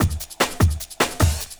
50 LOOP01 -R.wav